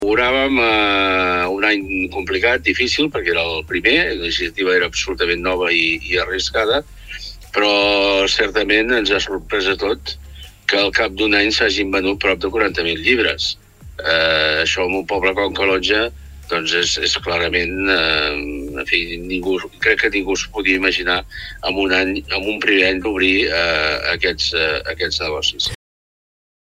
En vam parlar al Supermatí amb Jordi Soler, alcalde de Calonge i responsable de l’àrea de Cultura.